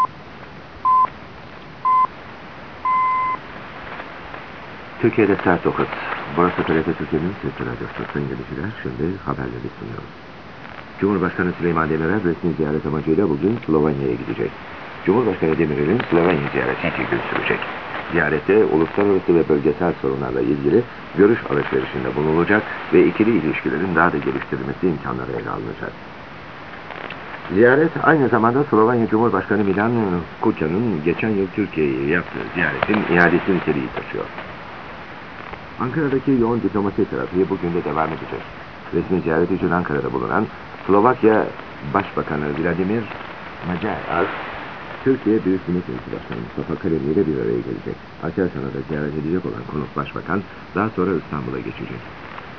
音声ファイル（ソニーSW1000Tと八木アンテナ使用、1997年4月4日録音）
ニュース